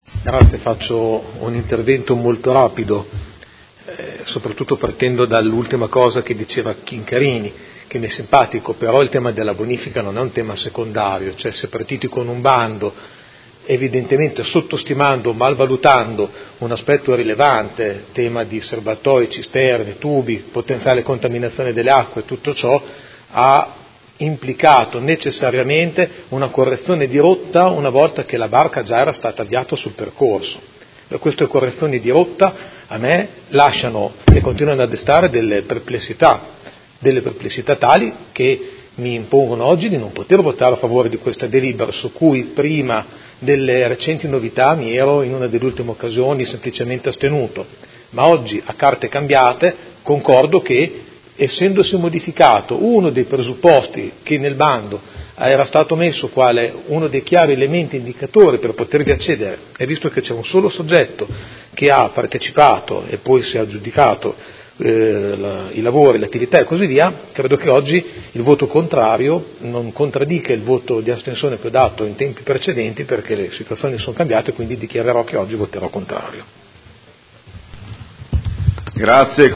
Giuseppe Pellacani — Sito Audio Consiglio Comunale
Seduta del 04/04/2019 Dichiarazione di voto.